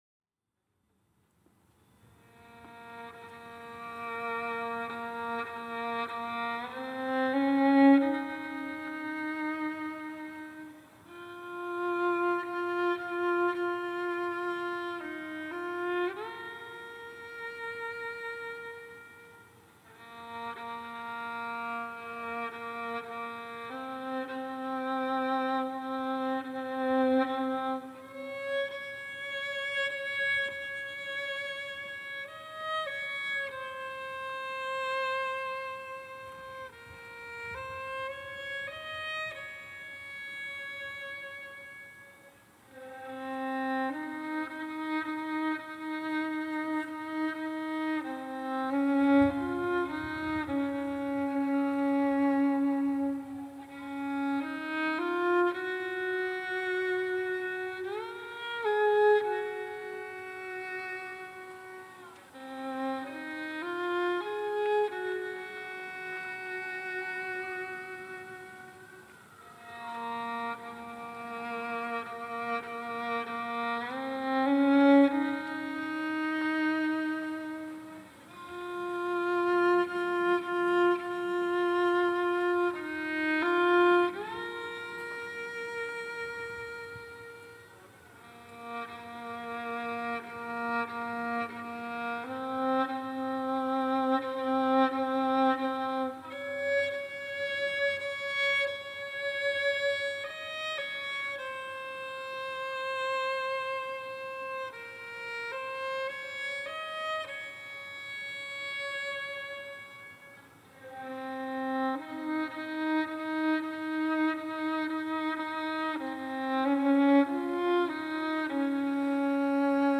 These recordings feature performances by students of Sri Chinmoy from Sri Chinmoy’s 2013 Mahasamadhi anniversary (Oct. 11). The nine tracks feature performances from two groups Ashru Dhara, and Dundhubi – playing the Ehru.